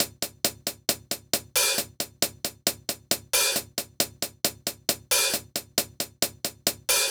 Jfx Hats.wav